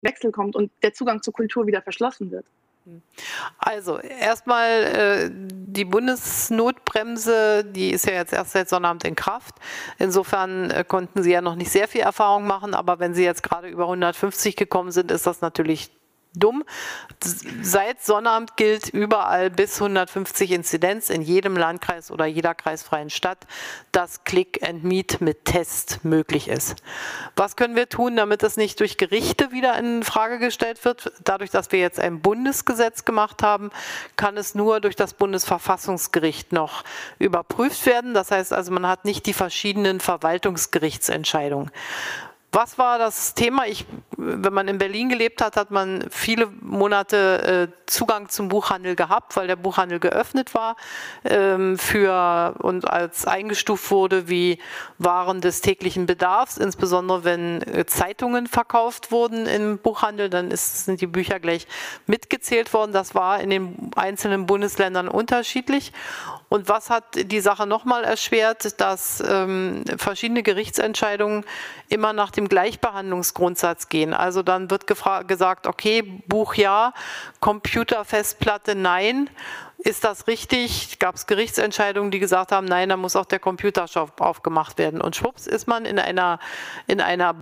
In ihrem „Gespräch mit Kunst- und Kulturschaffenden“als Video verfügbar in der Mediathek der Bundesregierung –
sagt die Kanzlerin wörtlich : „Und was hat die Sache noch mal erschwert?